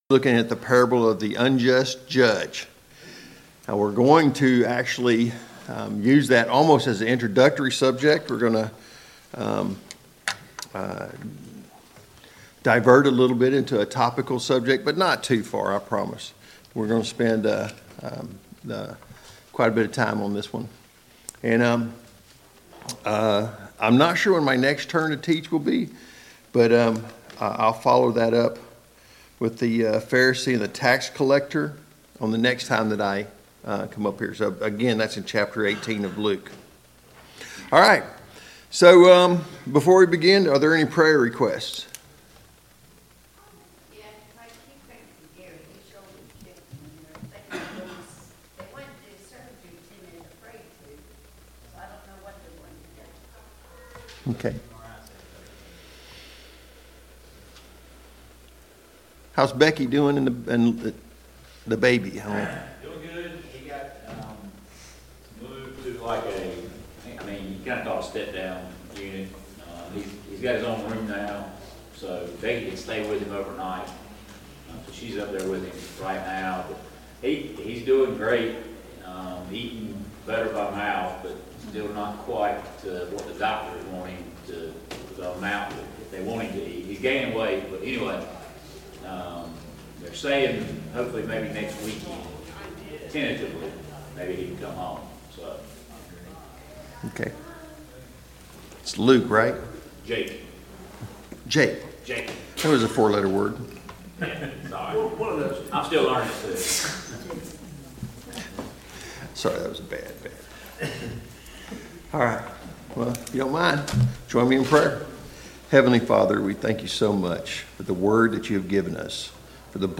Sunday Morning Bible Class « What can we learn from the life of the Apostle Peter?